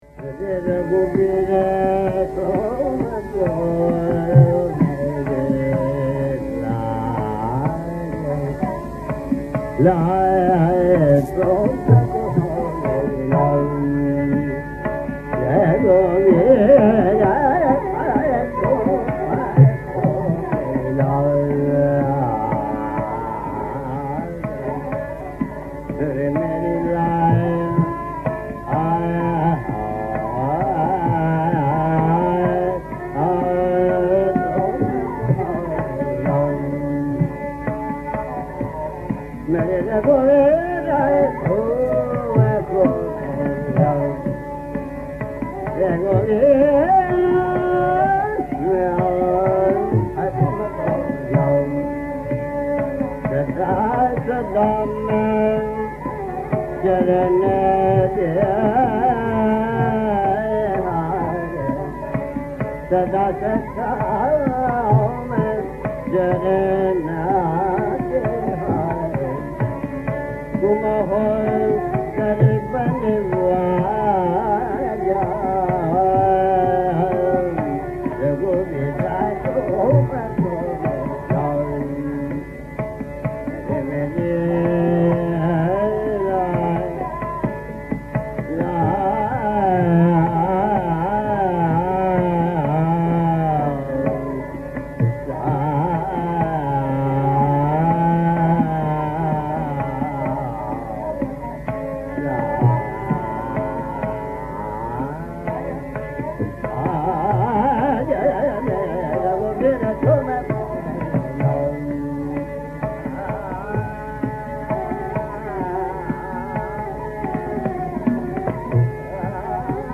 Raga Piloo